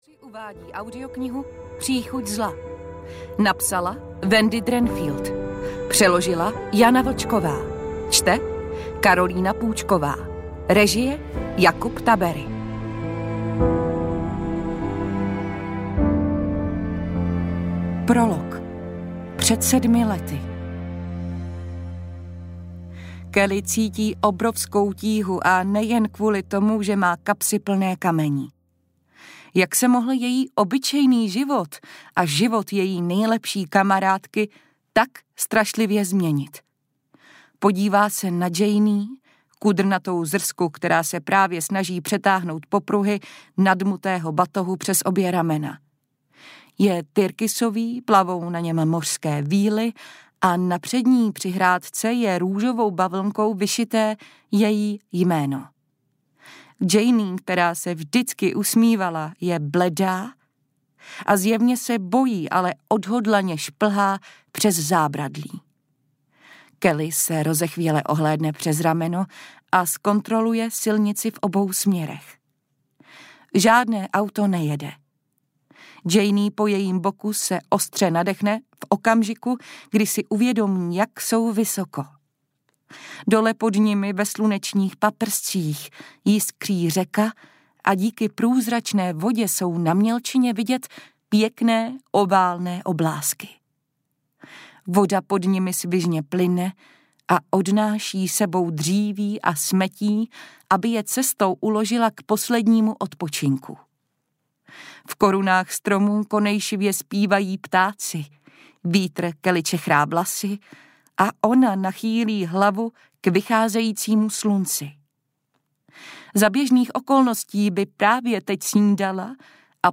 Příchuť zla audiokniha
Ukázka z knihy